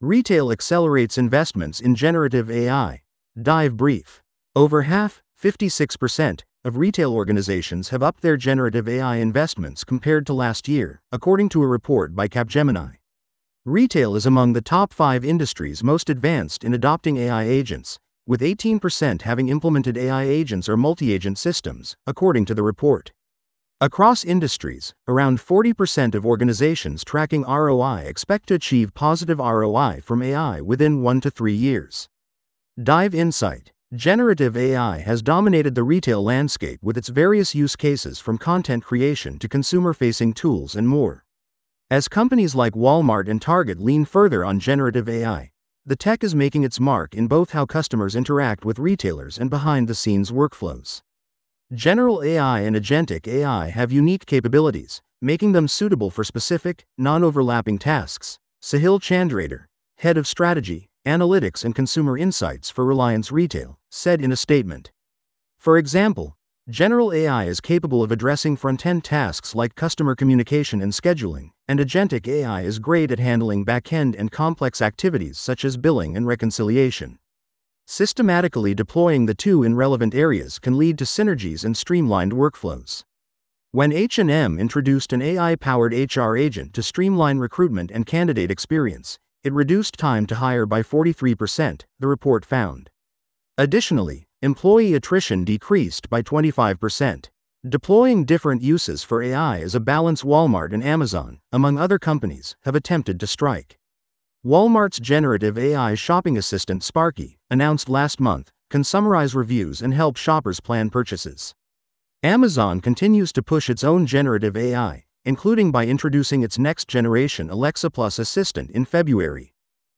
This audio is auto-generated.